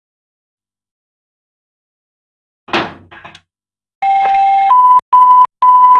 Удар двери и домофон